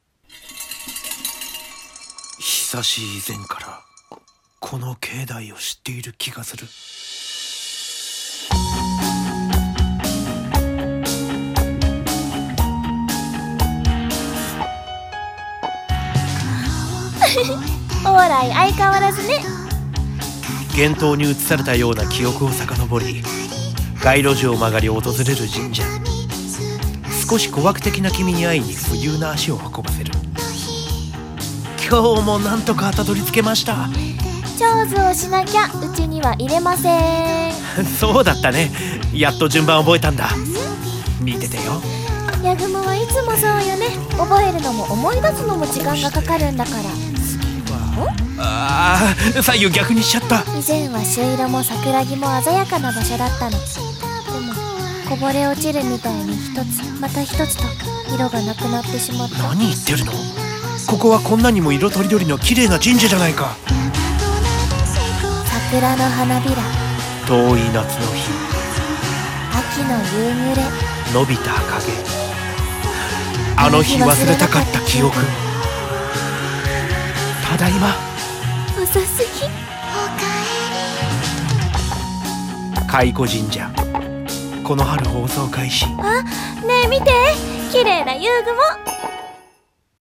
CM風声劇「懐古神社